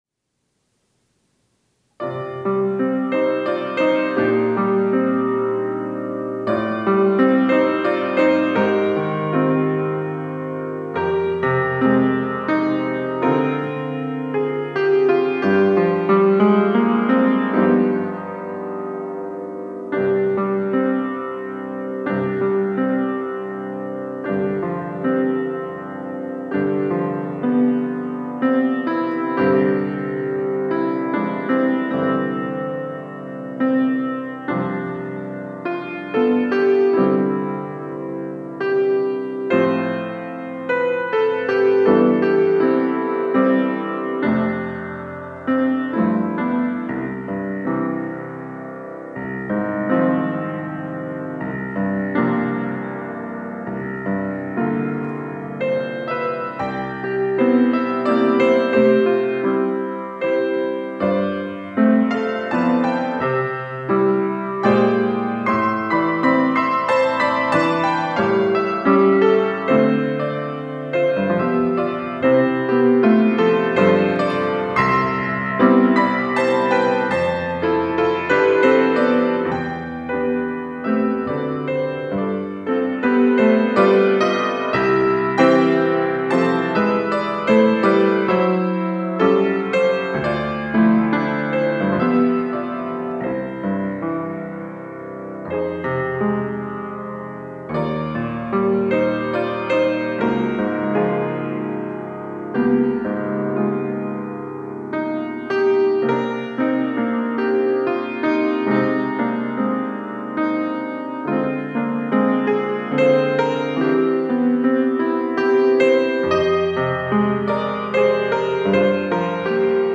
특송과 특주 - 내 주의 보혈은